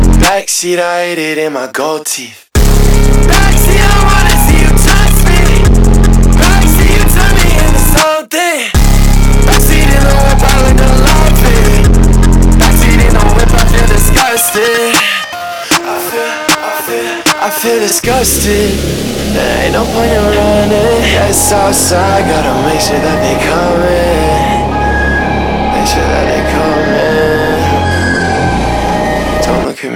Скачать припев
2024-10-04 Жанр: Поп музыка Длительность